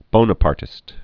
(bōnə-pärtĭst)